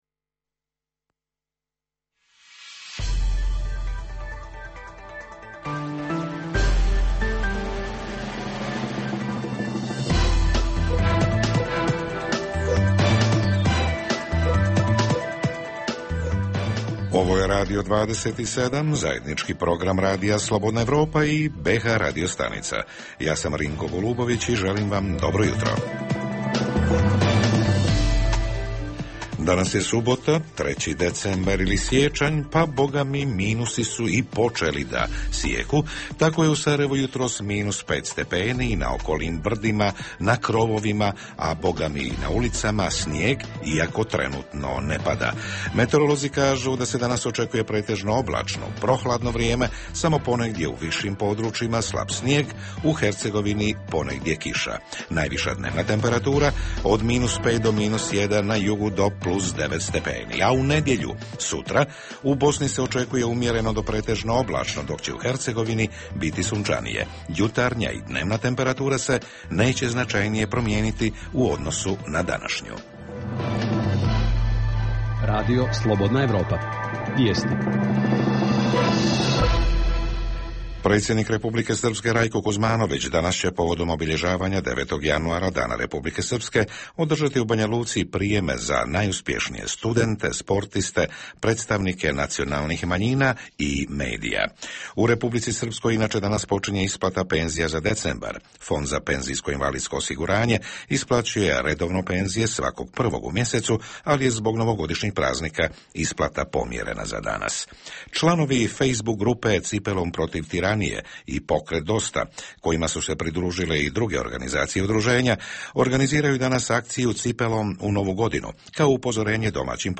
Jutarnji program za BiH koji se emituje uživo. Prvi vikend Nove 2009 godine počinjemo laganim temama.
Redovni sadržaji jutarnjeg programa za BiH su i vijesti i muzika.